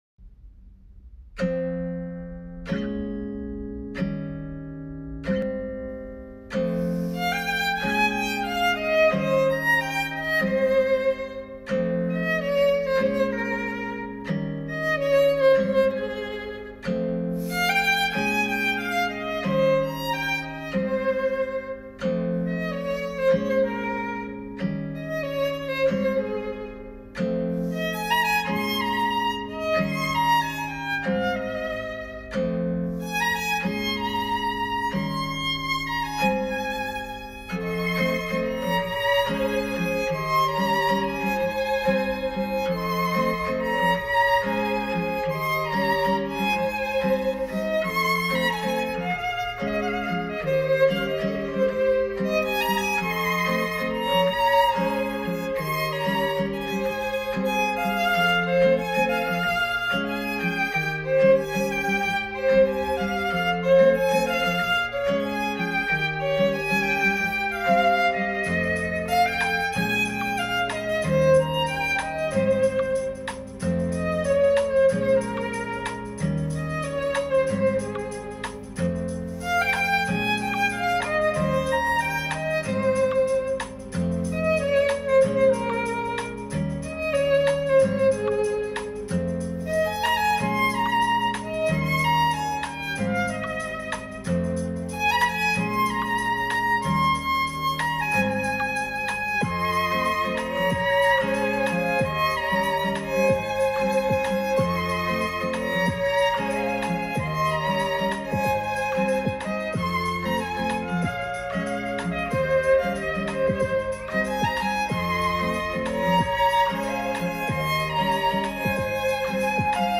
اجرا با ویولن